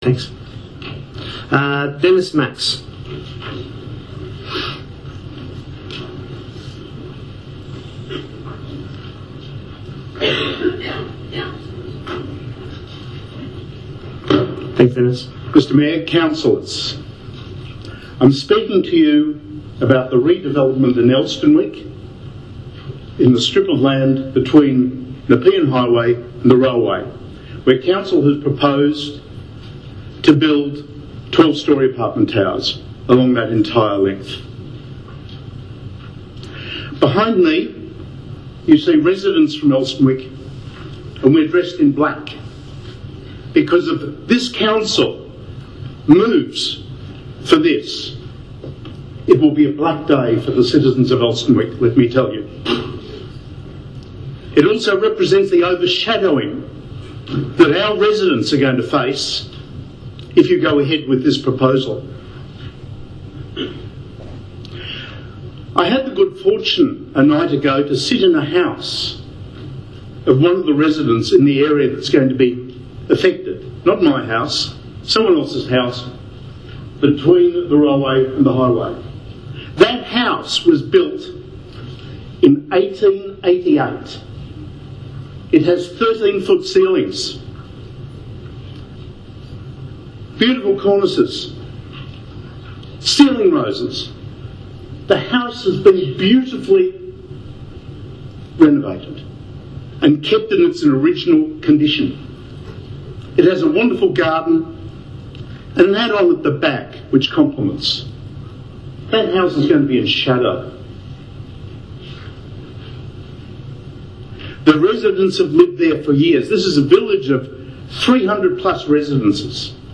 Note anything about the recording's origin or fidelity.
The following recording features part of the ‘community participation’ segment from last night’s council meeting.